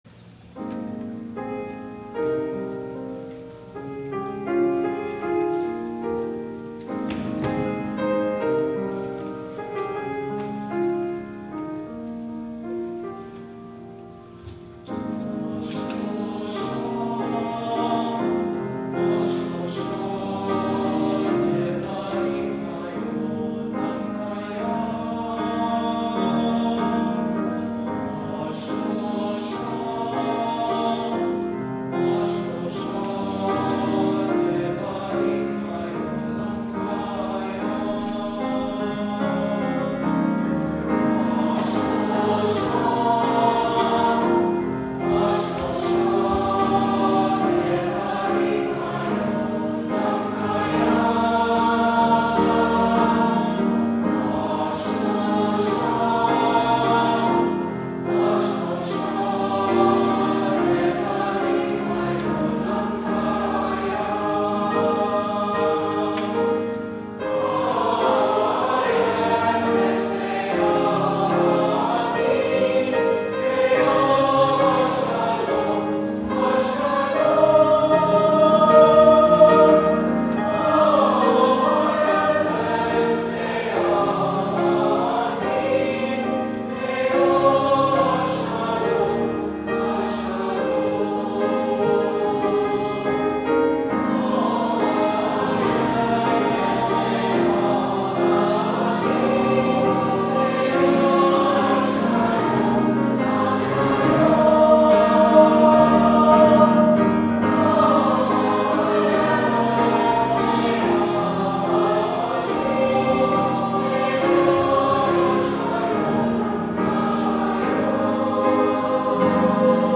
THE PRELUDE